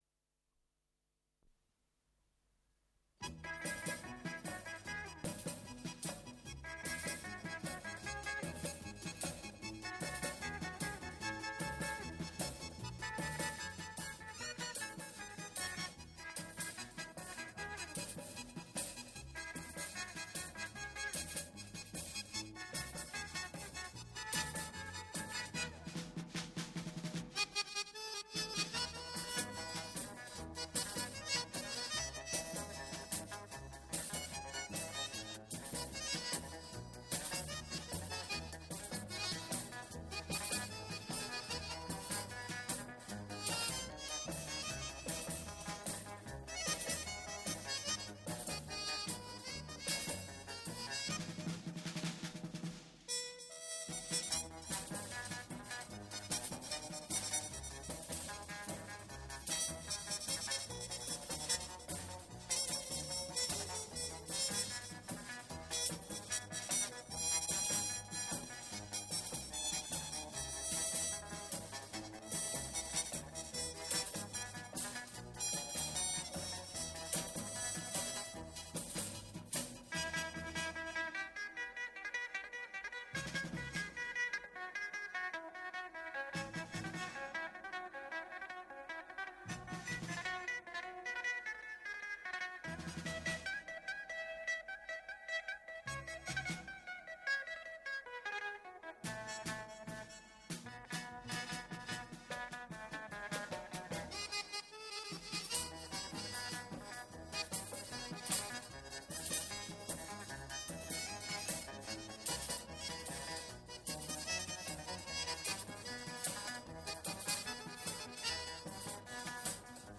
Исполнитель инструментальный ансамбль .